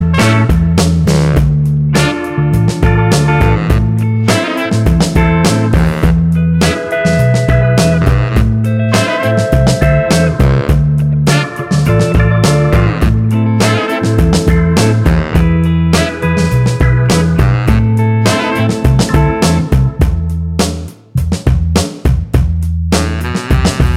Minus Main Guitar Pop (2000s) 4:12 Buy £1.50